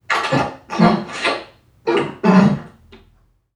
NPC_Creatures_Vocalisations_Robothead [40].wav